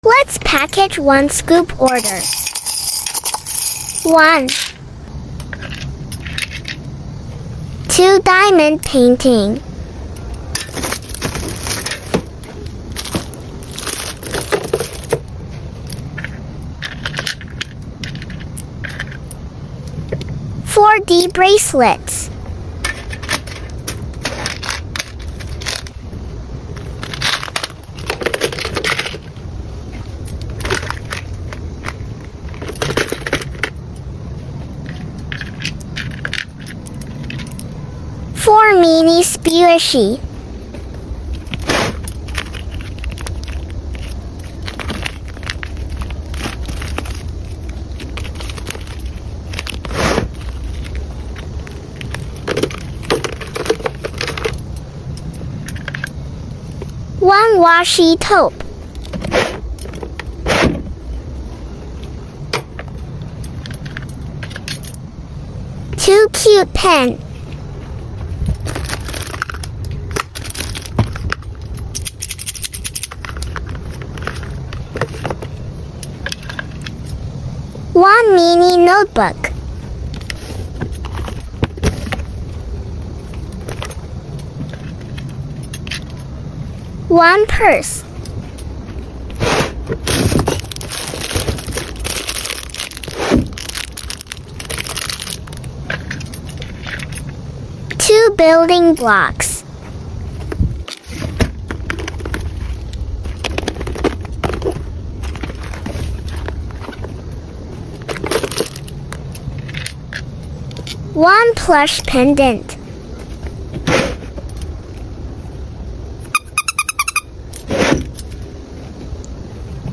Asmr packing video!! Hope you sound effects free download